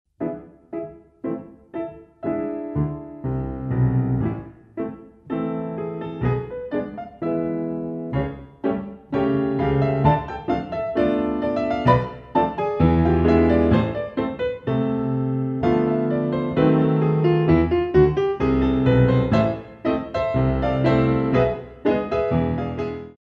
Marche